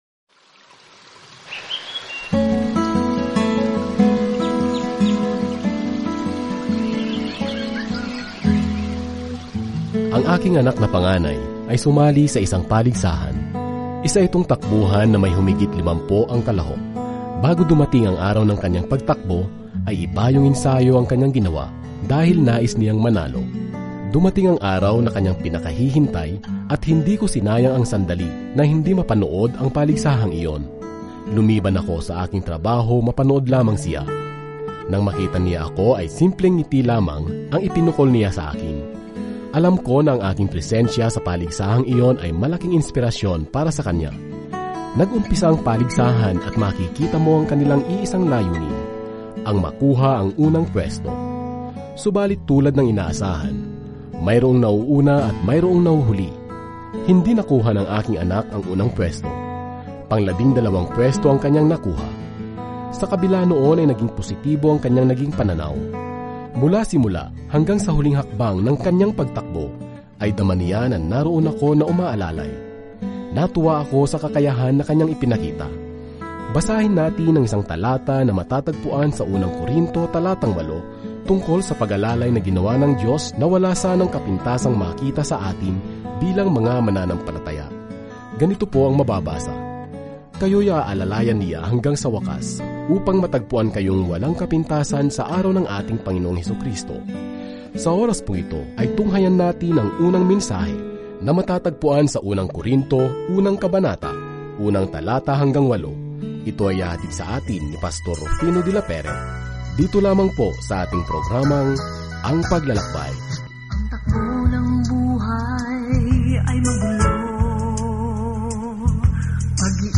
Araw-araw na paglalakbay sa 1 Corinthians habang nakikinig ka sa audio study at nagbabasa ng mga piling talata mula sa salita ng Diyos.